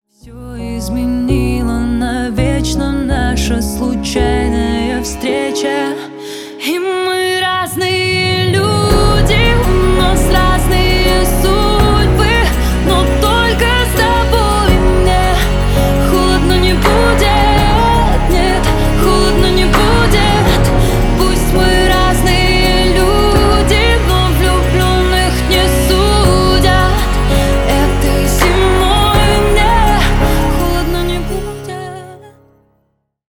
• Качество: 320 kbps, Stereo
Поп Музыка
спокойные